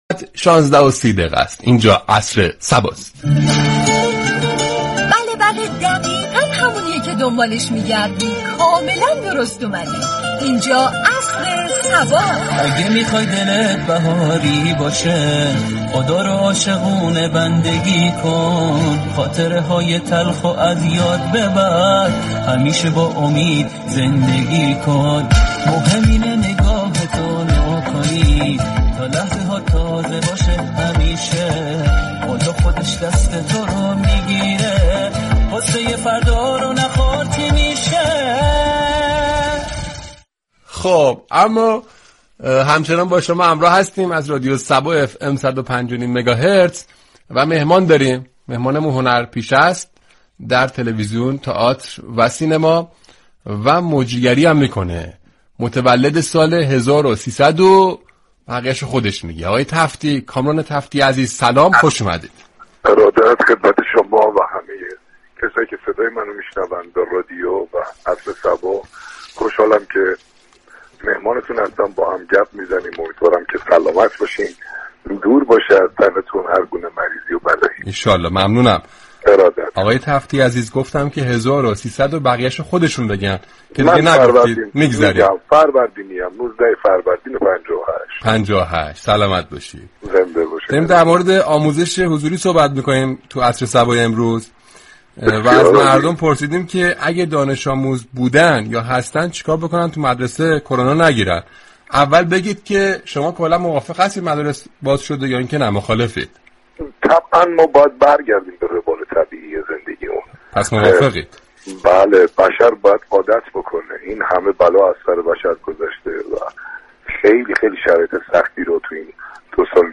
رادیو صبا در برنامه عصر صبا میزبان كامران تفتی شد و با وی درباره بازگشایی مدارس گفتگو كرد.
این برنامه با بخش های متنوع در فضایی شاد تقدیم مخاطبان می شود، یكشنبه ششم آذر در بخش گفتگو این برنامه میزبان كامران تفتی بازیگر سینما و تلویزیون شد و با وی در باره بازگشایی مدارس و آموزش مجازی گفتگو صمیمی داشت.